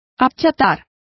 Complete with pronunciation of the translation of flatten.